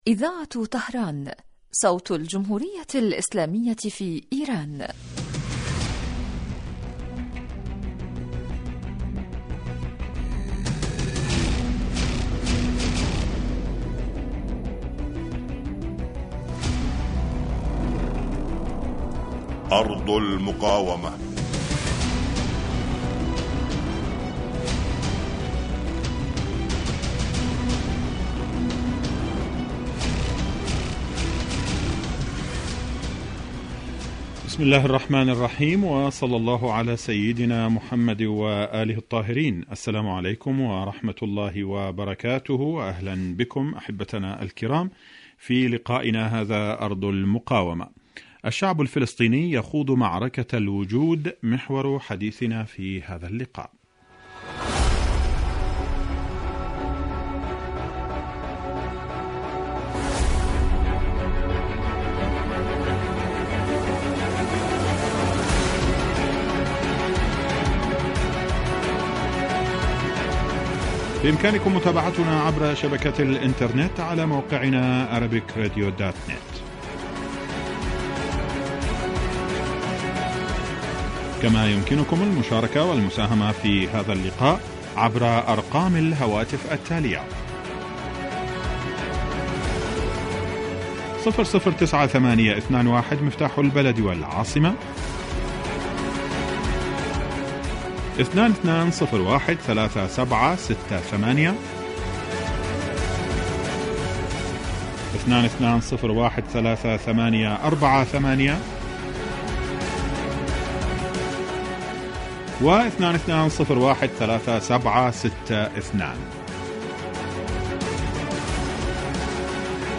برنامج إذاعي حي لنصف ساعة يتناول بالدراسة والتحليل آخر التطورات والمستجدات على صعيد سوريا والأردن وفلسطين المحتلة ولبنان.
يستهل المقدم البرنامج بمقدمة يعرض فيها أهم ملف الأسبوع ثم يوجه تساؤلاته إلى الخبراء السياسيين الملمين بشؤون وقضايا تلك الدول والذين تتم استضافتهم عبر الهاتف .